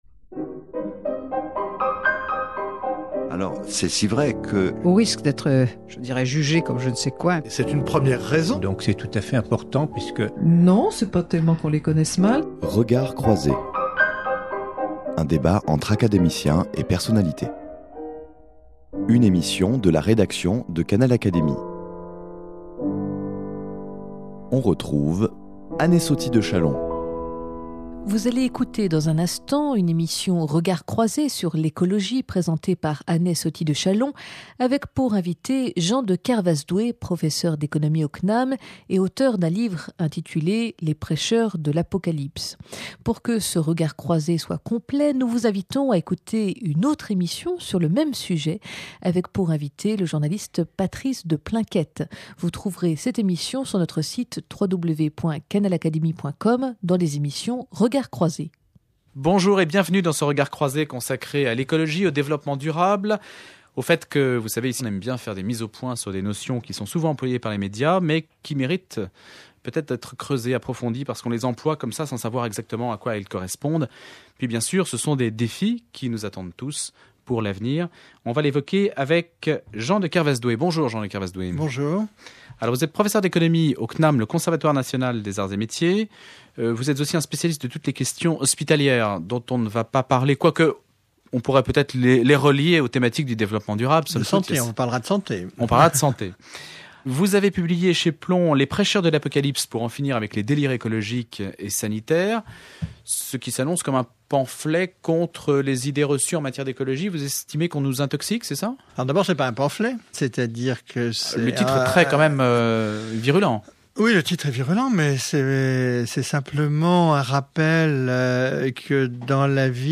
Dans cet entretien mené sur un mode contradictoire, Jean de Kervasdoué illustre l’étendue de ses connaissances par la précision de ses arguments.